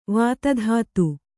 ♪ vāta dhātu